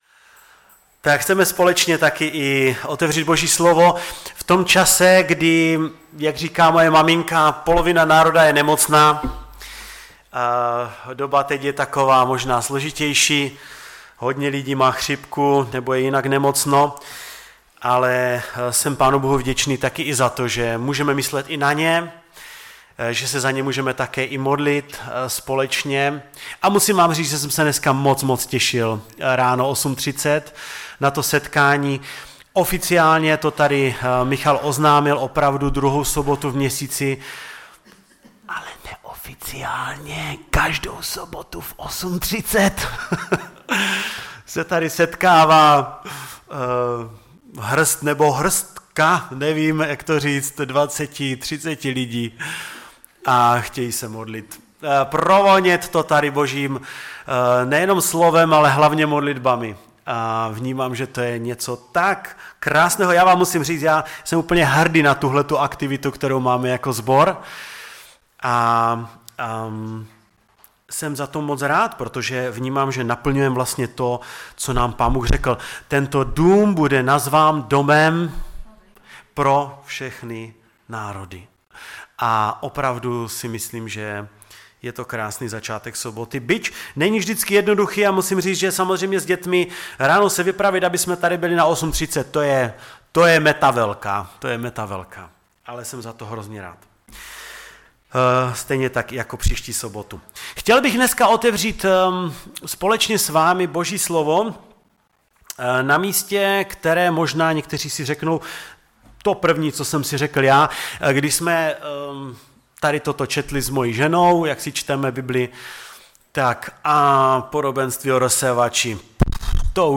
Kázání
Kazatel